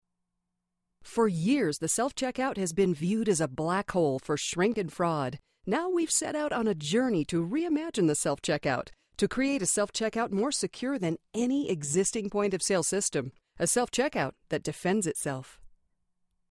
Once converted through All2mp3 the sound becomes tinny and distorted.
Nice voice.
I applied the Audiobook Mastering Suite of tools and your clip would pass submission with no further work.
It’s super common for home producers to fail noise and you didn’t. How are you recording, with what microphone and environment?